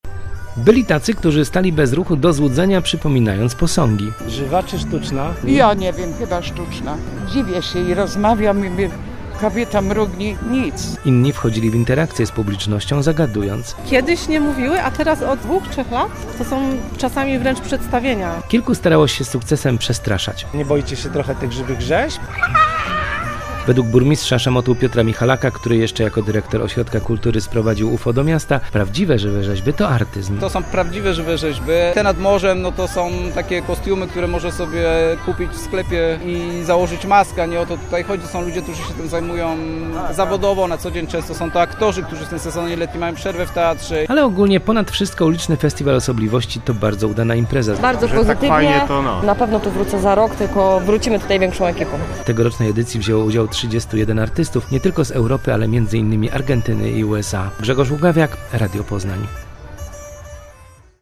- mówił burmistrz.
- mówili uczestnicy.